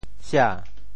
How to say the words 冩 in Teochew？
冩 Radical and Phonetic Radical 冖 Total Number of Strokes 14 Number of Strokes 12 Mandarin Reading xiě TeoChew Phonetic TeoThew sia2 文 Chinese Definitions 写 <动> (形声。